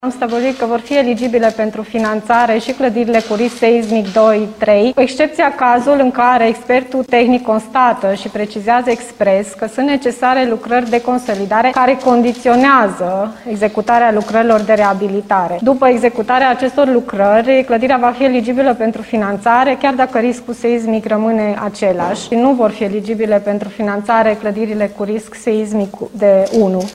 Vor putea accesa fonduri și cei care locuiesc în clădiri cu risc seismic, dar cu câteva condiții, explică consilierul local, Paula Romocean.